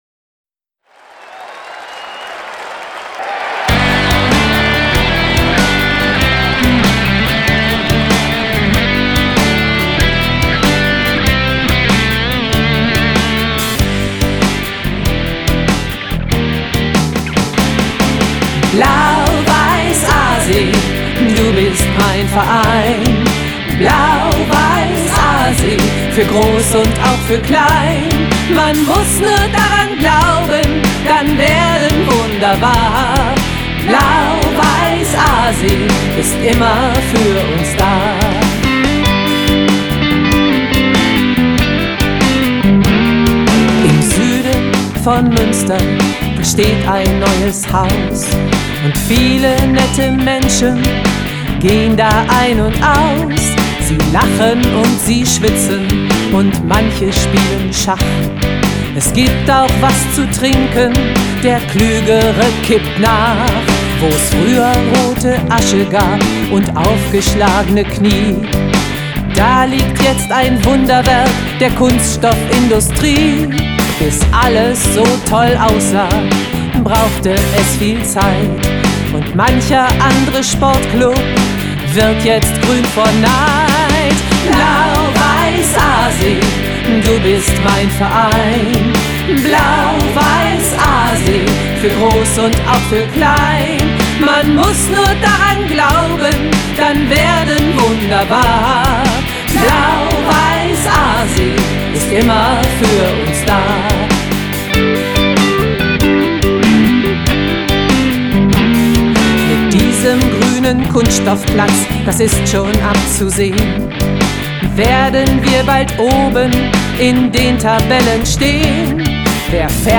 Der "Blau-Weiß-Aasee-Song". Die neue Vereinshymne feierte dort eine umjubelte Premiere.